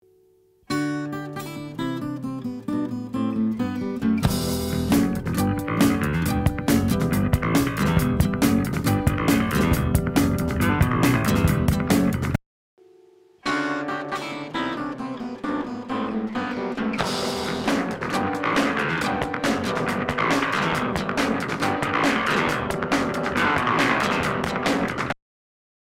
sampled first, then playback…